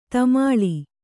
♪ tamāḷi